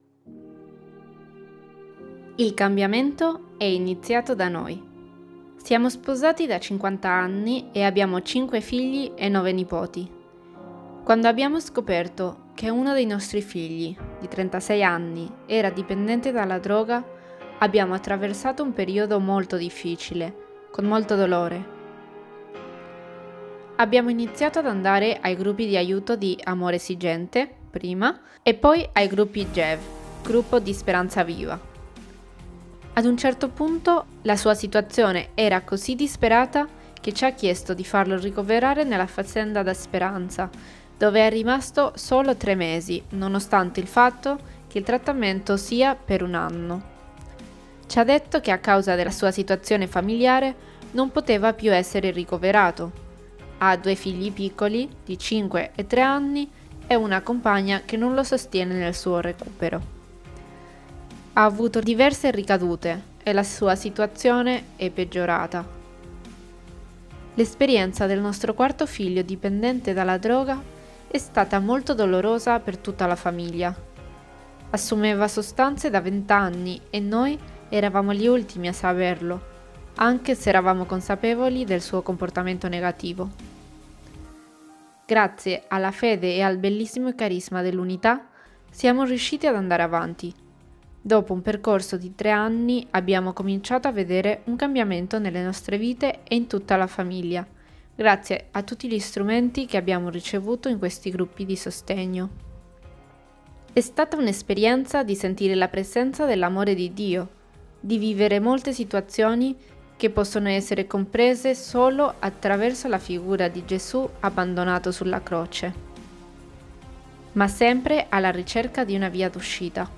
Persona e famiglia > Audioletture